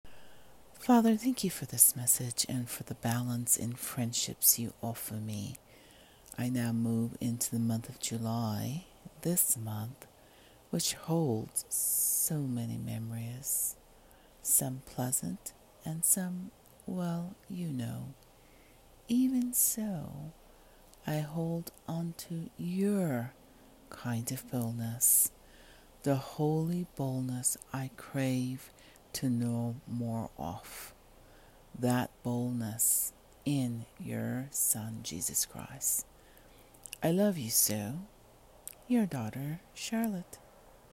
My prayer:
(Although in my very early wake up voice 🙂 Listen to me read this prayer)